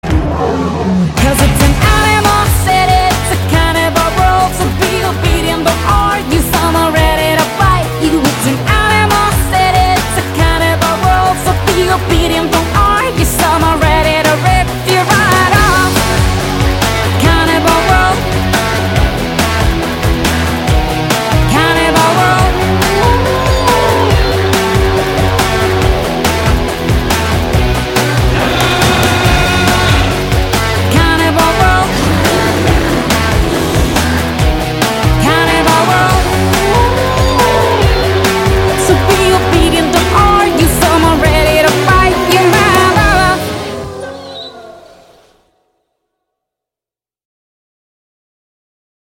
Нарезка на звонок